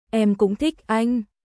Em cũng thích anhエム クン ティック アイン私もあなたが好きです